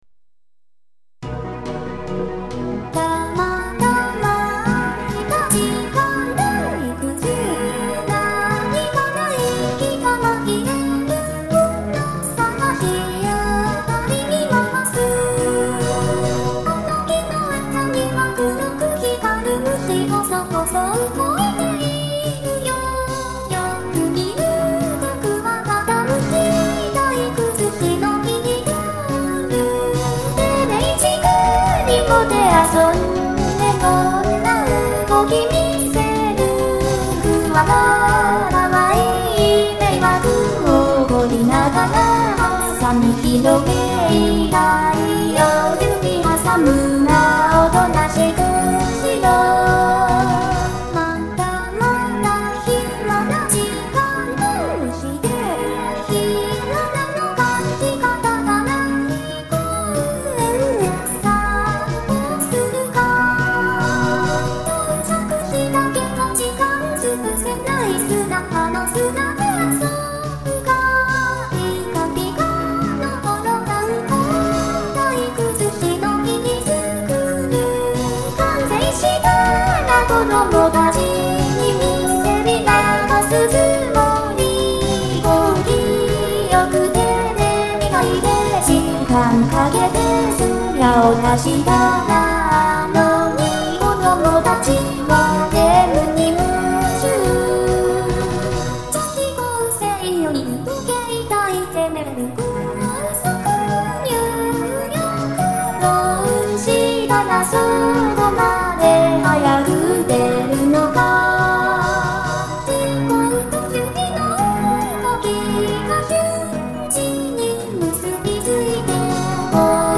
※創作ボーカロイドです。
〜ボーカル版〜